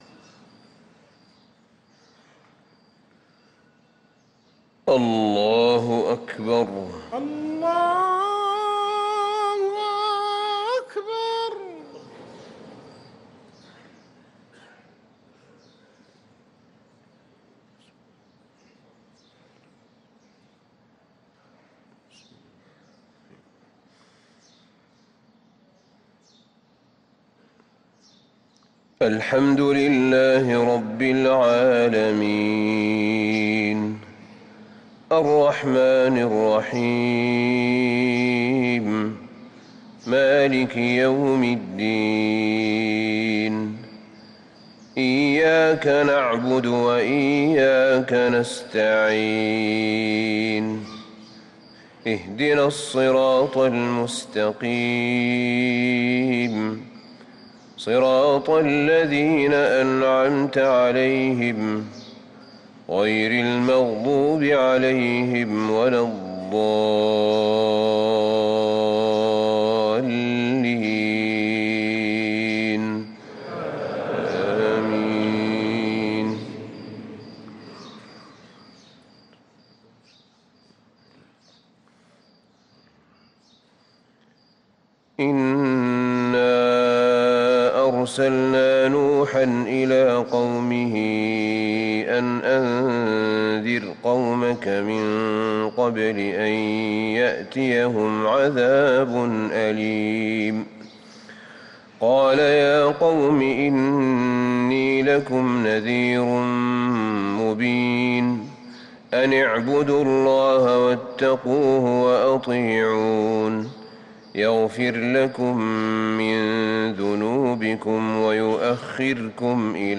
صلاة الفجر للقارئ أحمد بن طالب حميد 25 شعبان 1444 هـ
تِلَاوَات الْحَرَمَيْن .